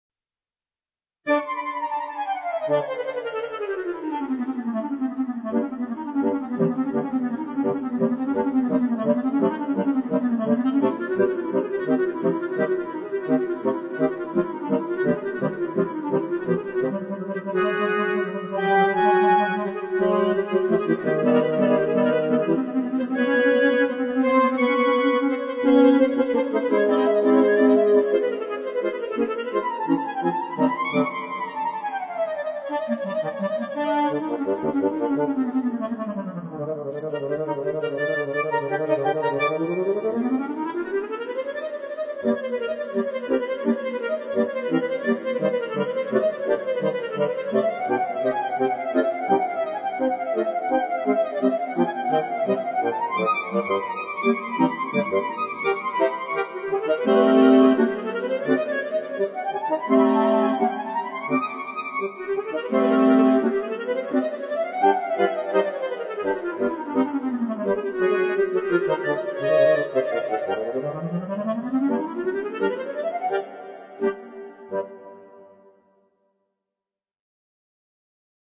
B♭ Clarinet 1 B♭ Clarinet 2 B♭ Clarinet 3 Bass Clarinet
单簧管四重奏
古典